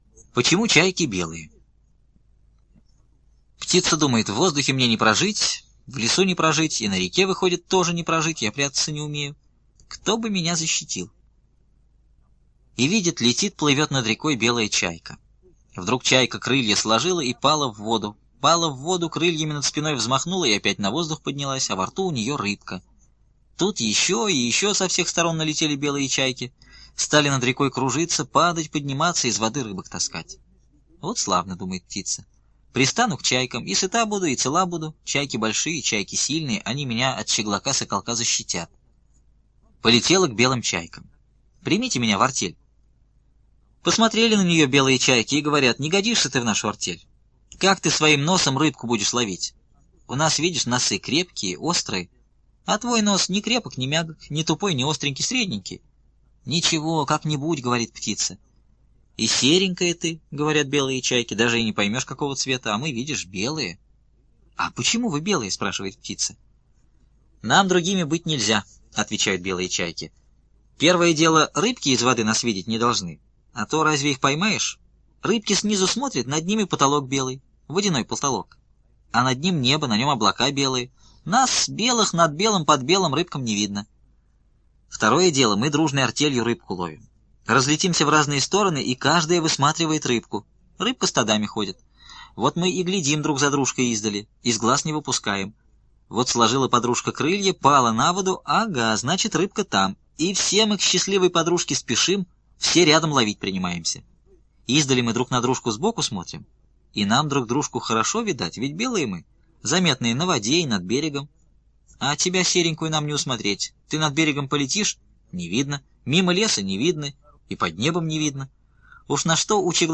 Почему чайки белые - аудиосказка Бианки - слушать онлайн | Мишкины книжки